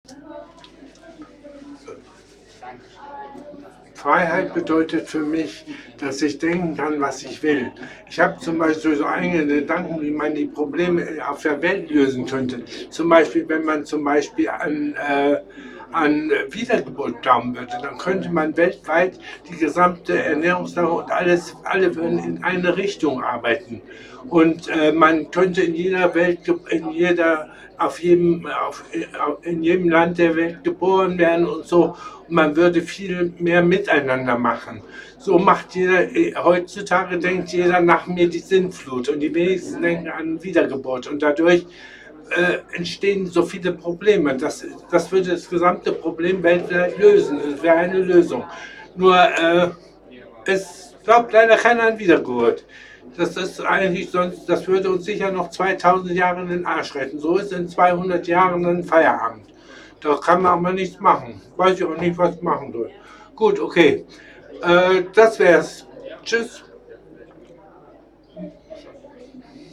Standort der Erzählbox:
MS Wissenschaft @ Diverse Häfen